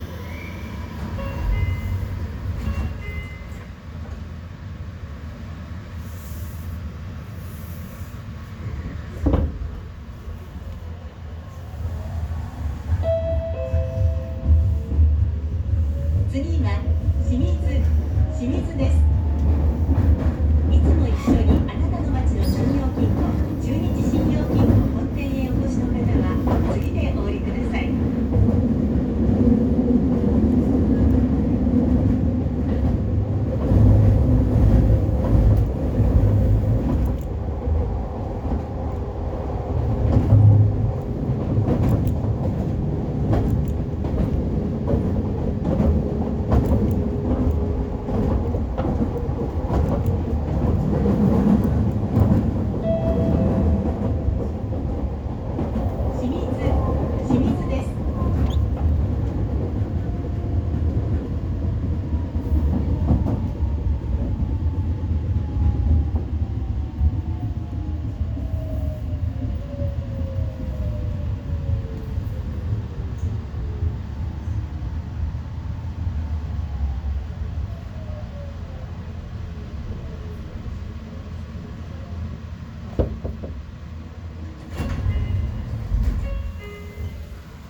・3150系・3300系(東芝IGBT)走行音
【瀬戸線】東大手→清水
上記と比べて東芝の編成はモーター音が殆ど目立たなくなりました。趣味的にはつまらないのかもしれませんが、静粛性は格段に上がっています。